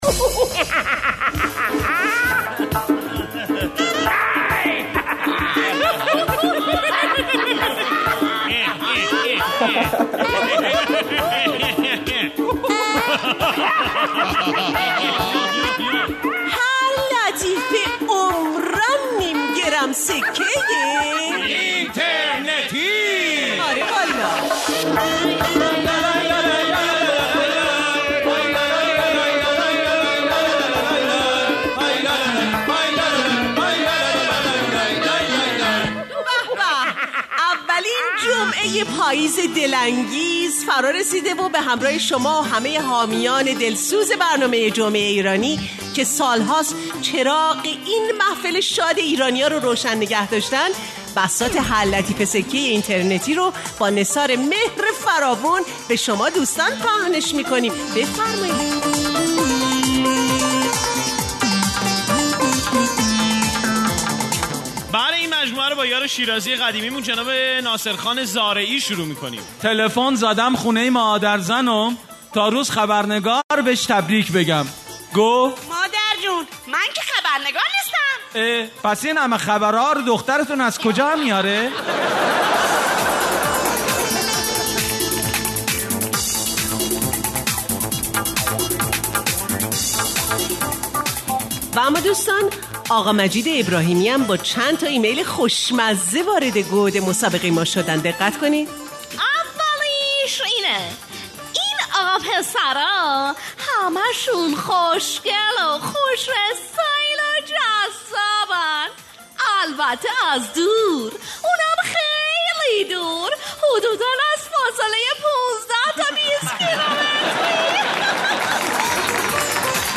• برنامه طنز و شاد و روز های جمعه